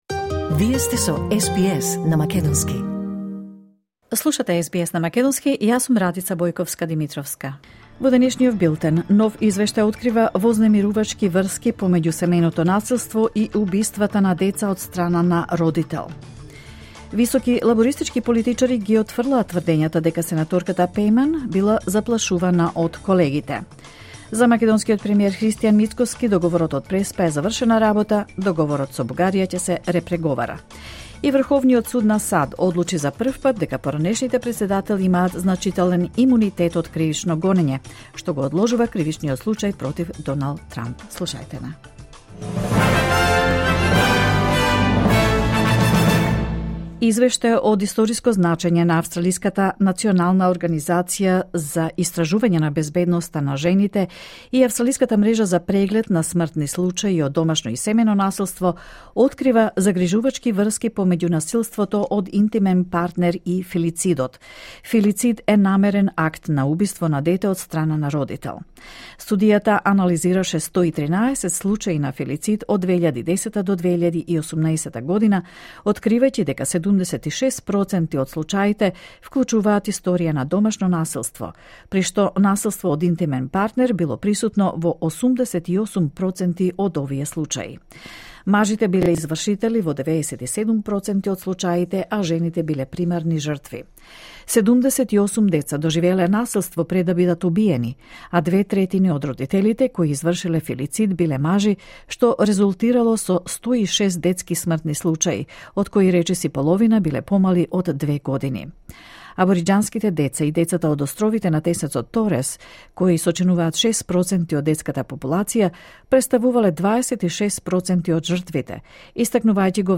Вести на СБС на македонски 2 јули 2021
SBS News in Macedonian 2 July 2021